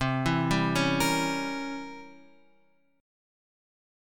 C 7th Flat 9th